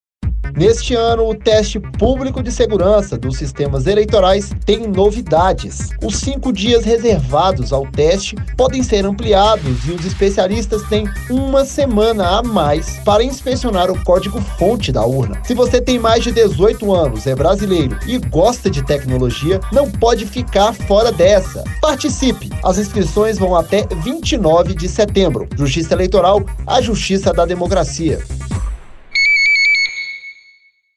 Spot: Conheça as novidades do Teste Público de Segurança dos sistemas eleitorais de 2021